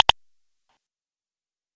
btnClick.mp3